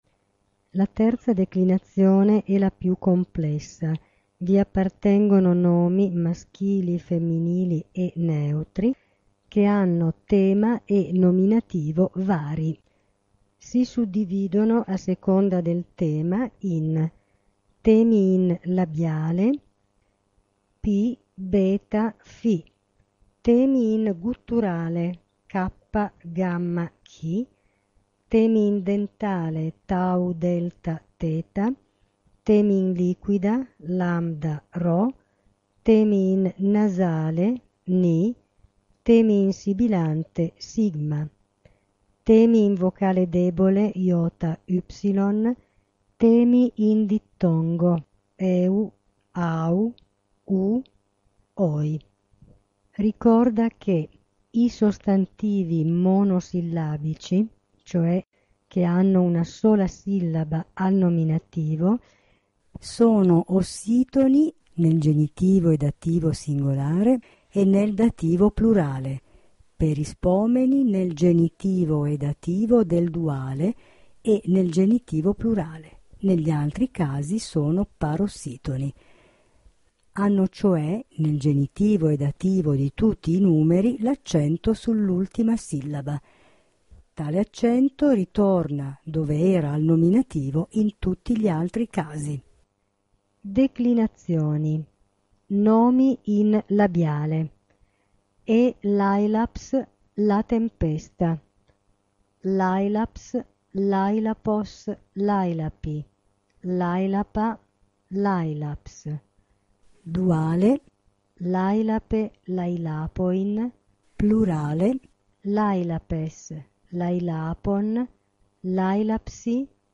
v. ) permette di sentire la lettura della introduzione alla terza declinazione e dei sostantivi in labiale